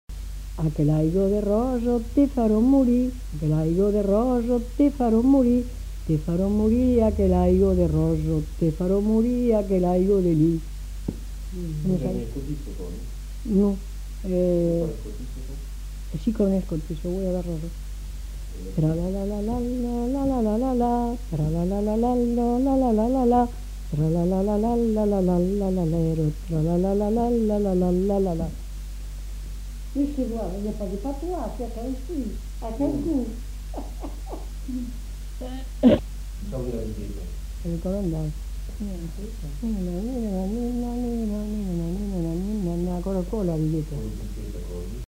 Lieu : Cancon
Genre : chant
Effectif : 1
Type de voix : voix de femme
Production du son : chanté ; fredonné
Danse : scottish